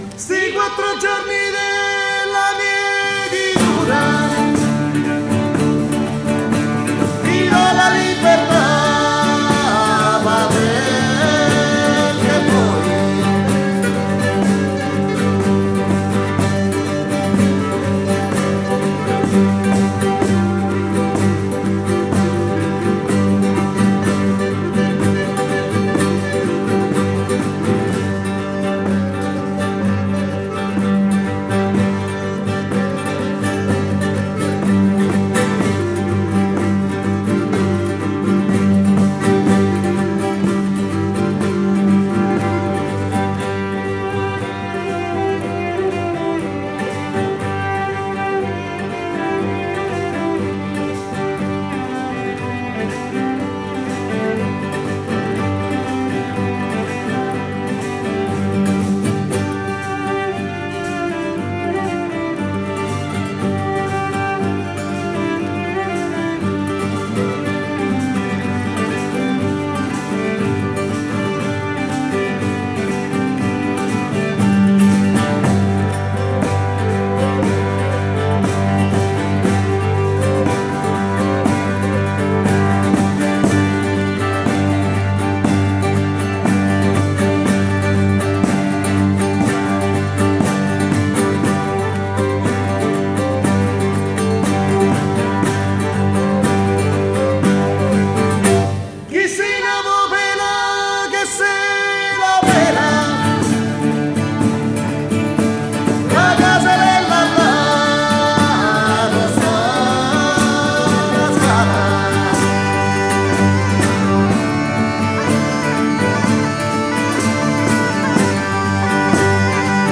voce, chitarra e bouzouki
violoncello e cori
zampogna, clarinetto e flauti
violino e chitarra
violino, fisarmonica e voce